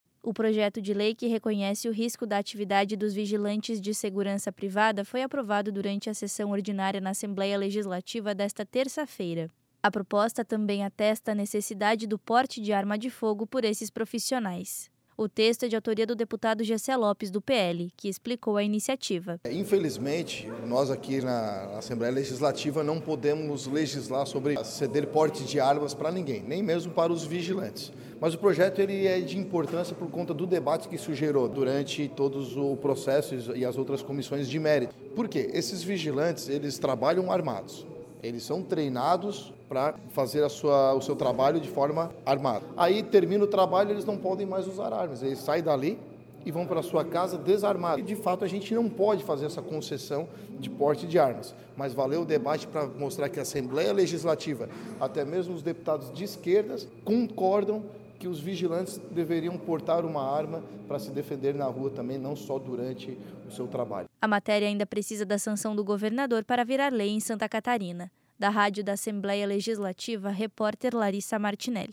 Entrevista com:
- deputado Jessé Lopes (PL), autor da matéria.